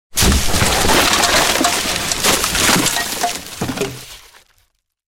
Звуки шкафа
Звук падающего шкафа с обрыва